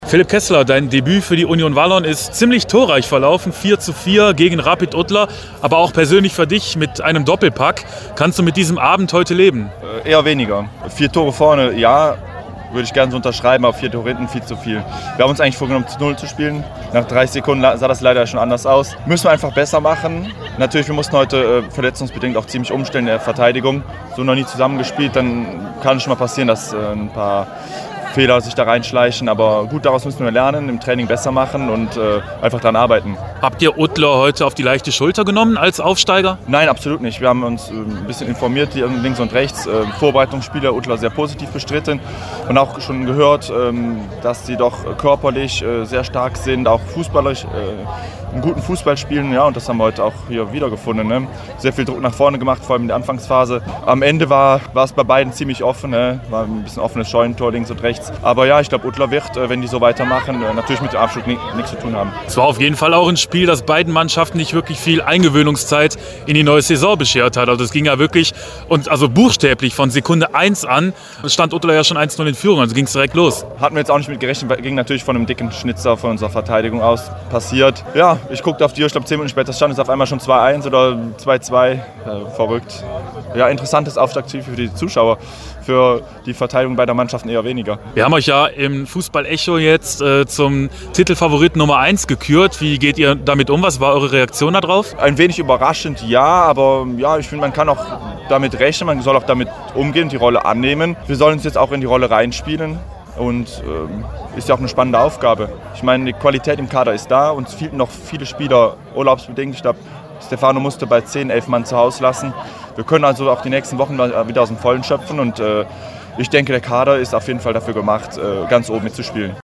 sprach mit den beiden Doppeltorschützen